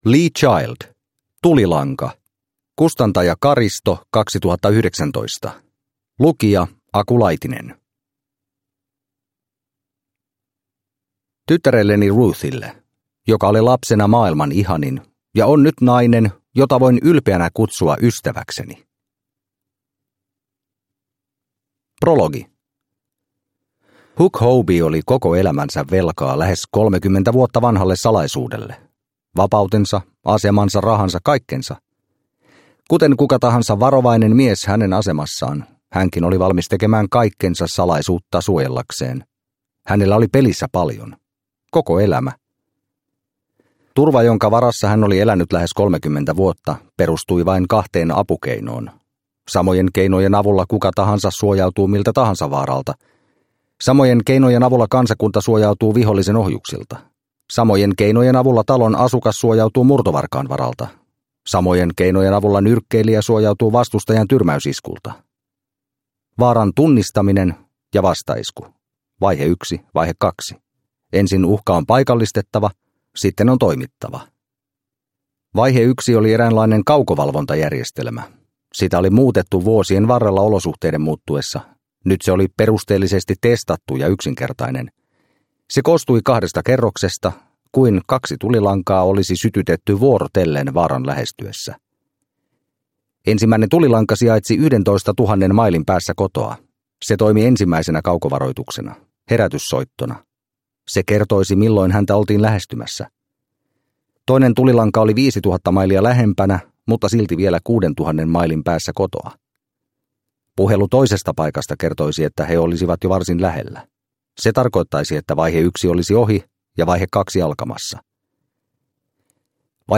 Tulilanka – Ljudbok – Laddas ner